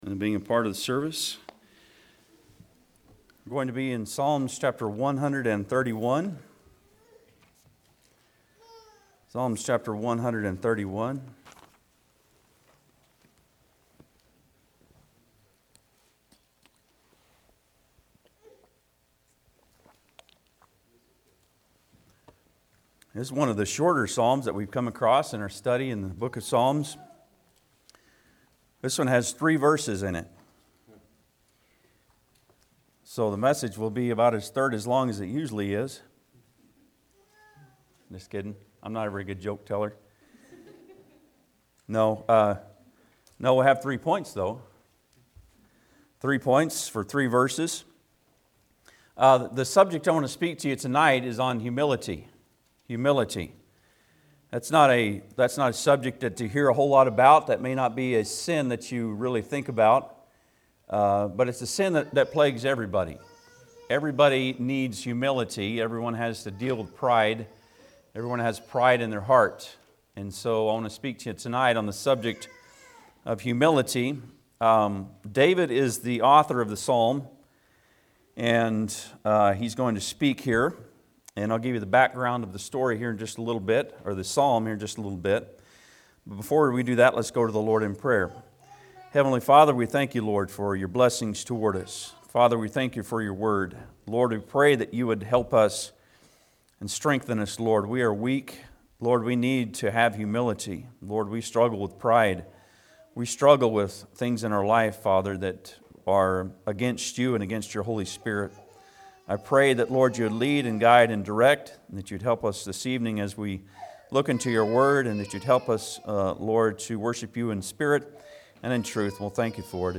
Psalms 131 Service Type: Sunday pm Bible Text